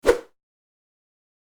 throw_egg.wav